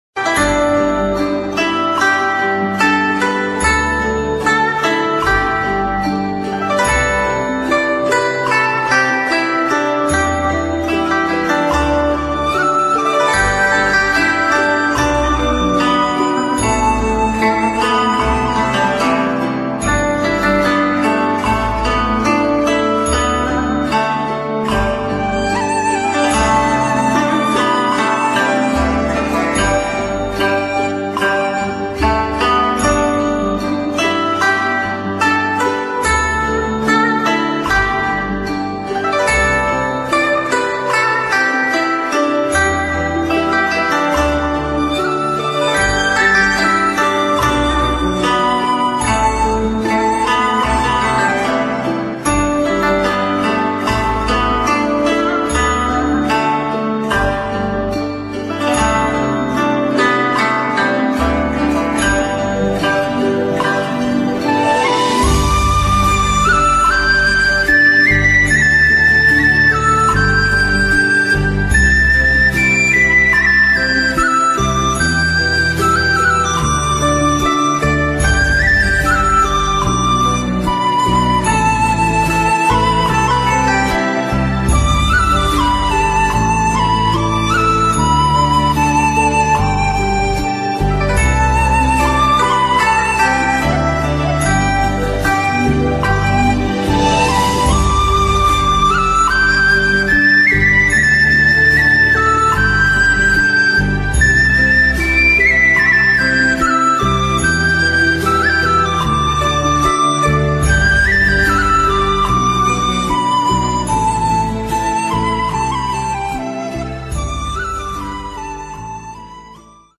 音频：意大利普拉托观音堂！(2022年12月15日释迦牟尼佛像开光大典和慈心素食活动)